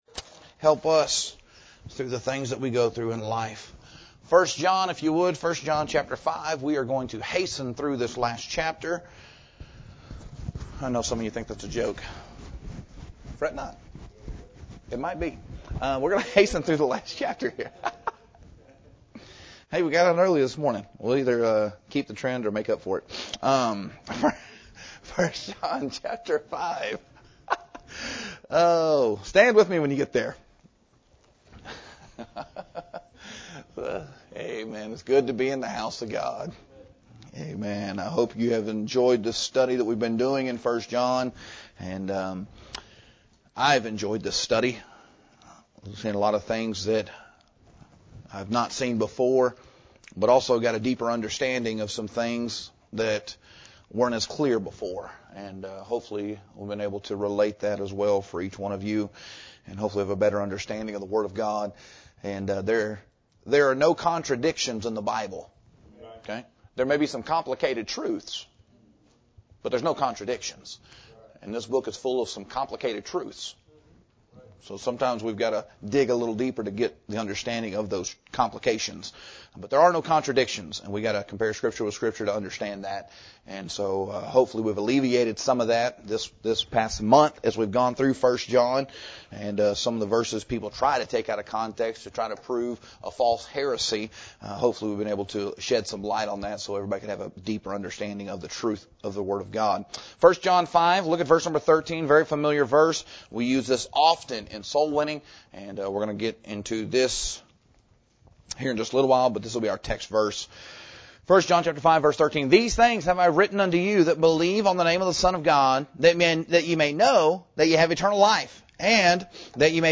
Who is God – Part 8 – Cornerstone Baptist Church | McAlester, OK